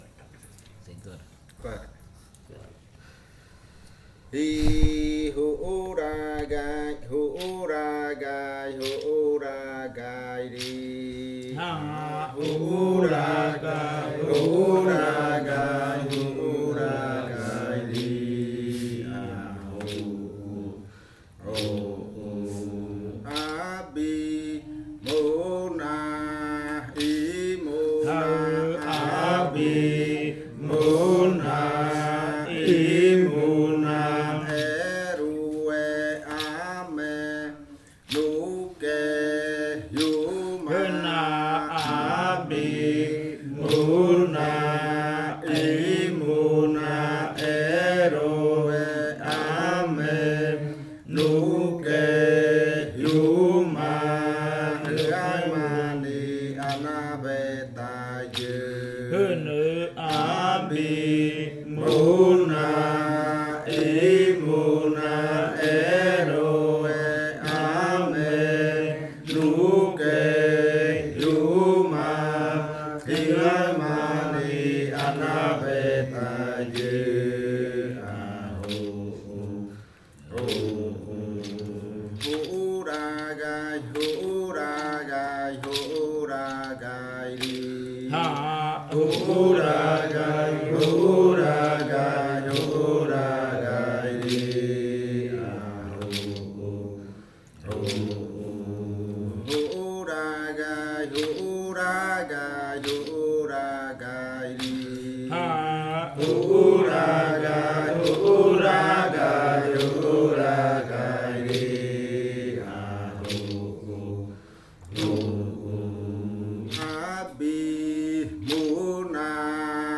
Canto de la variante jimokɨ
con el grupo de cantores sentado en Nokaido. Este canto hace parte de la colección de cantos del ritual yuakɨ murui-muina (ritual de frutas) del pueblo murui, colección que fue hecha por el Grupo de Danza Kaɨ Komuiya Uai con apoyo de la UNAL, sede Amazonia.
with the group of singers seated in Nokaido.